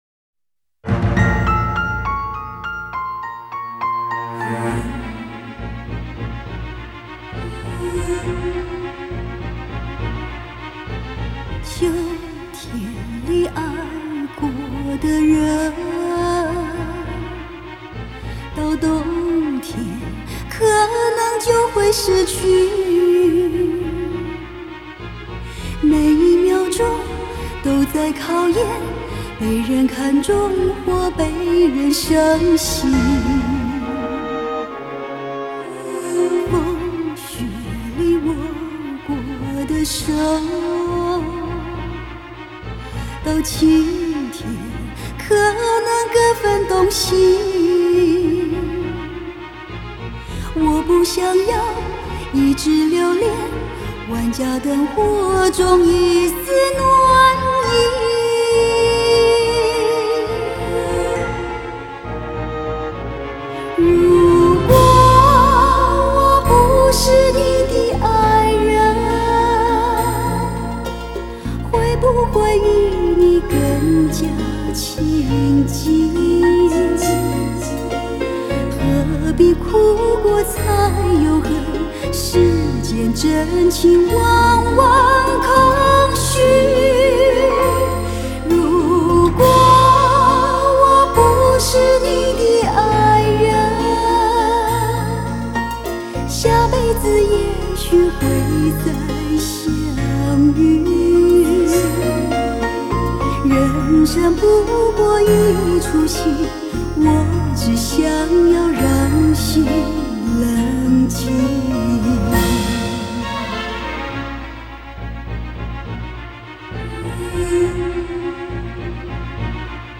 她唱歌似乎能够模拟人心底的感受，从她的歌曲中，我们无法回避的就是真诚，字里行间洋溢着最大意义的诚意和真情。
44.100 Hz;16 Bit;立体声